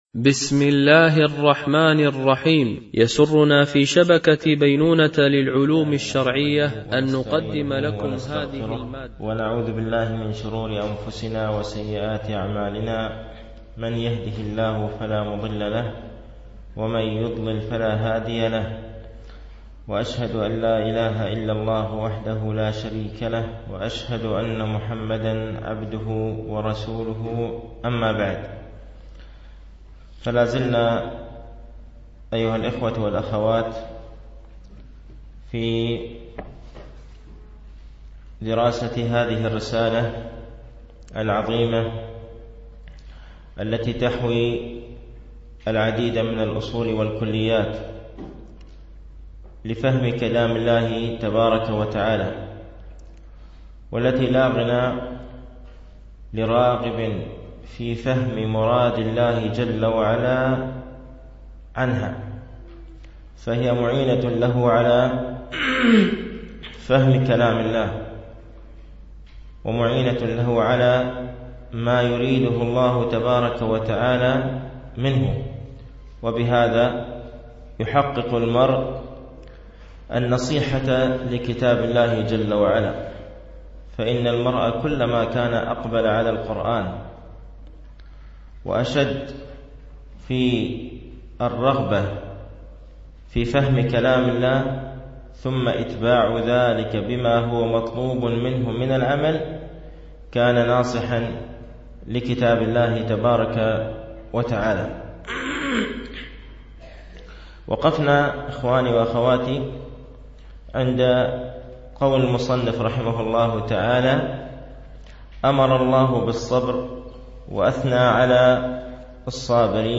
شرح أصول وكليات من أصول التفسير وكلياته للسعدي ـ الدرس السابع
التنسيق: MP3 Mono 22kHz 32Kbps (CBR)